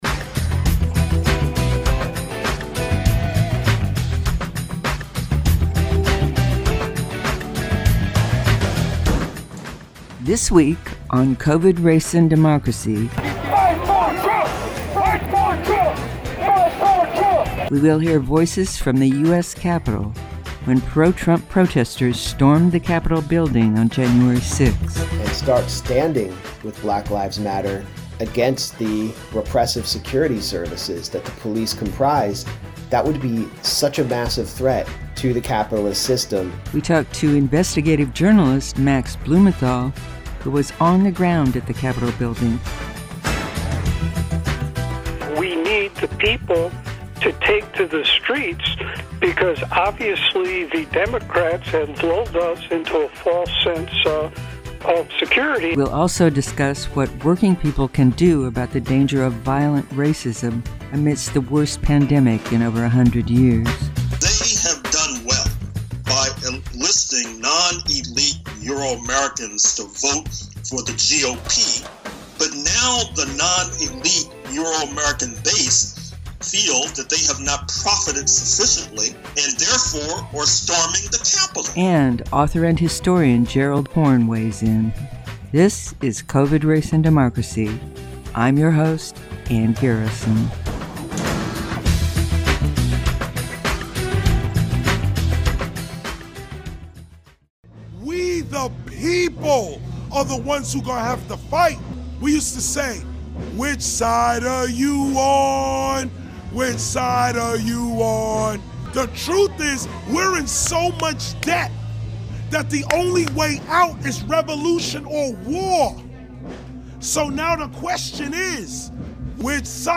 In this episode, we hear voices of those who came to Washington to protest on January 6th.